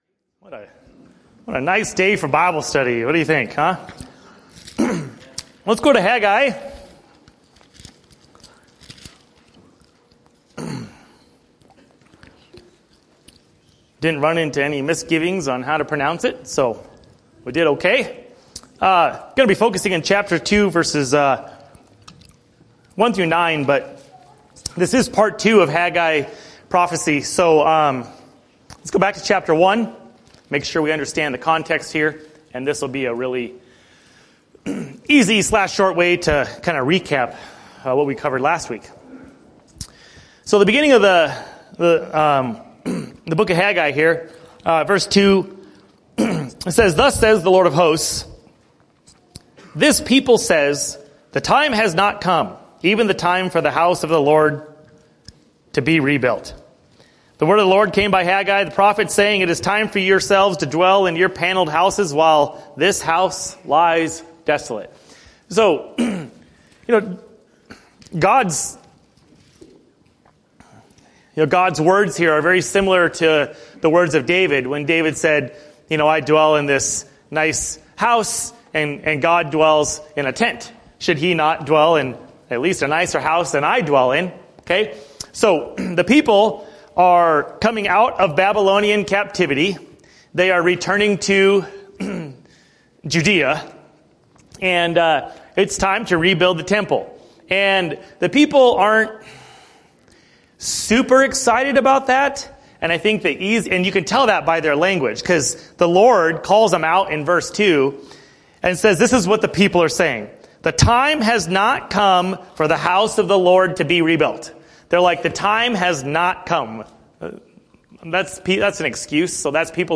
Tonight’s lesson was taught from the book of Haggai.